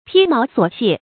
披毛索黡 pī máo suǒ yǎn
披毛索黡发音
成语注音 ㄆㄧ ㄇㄠˊ ㄙㄨㄛˇ ㄧㄢˇ